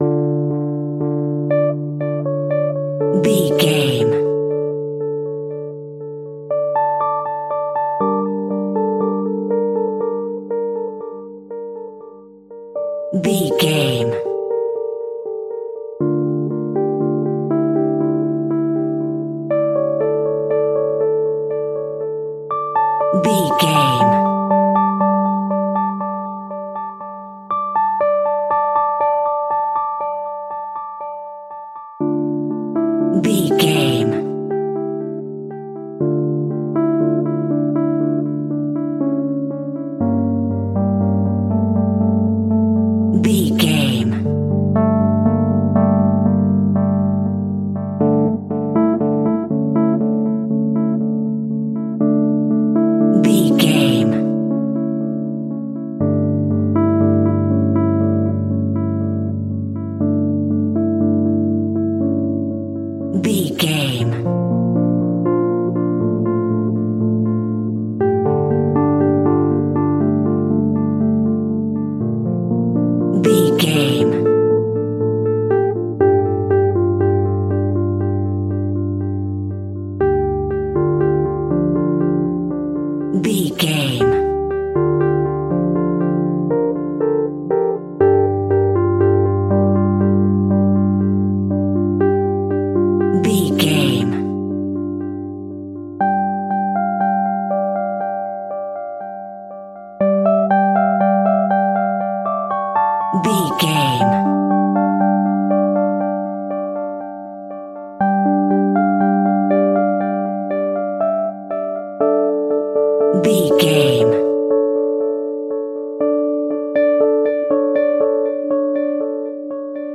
Aeolian/Minor
D
tension
ominous
dark
eerie
horror